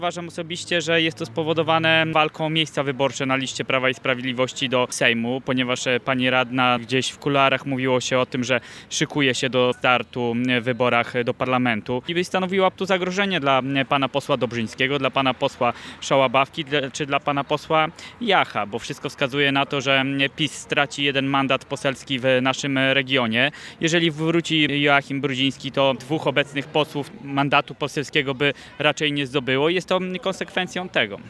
Radny Województwa, Wojciech Dorżynkiewicz, podejrzewa, że odsunięcie Jacyny Witt może mieć związek z przyszłorocznymi wyborami do parlamentu.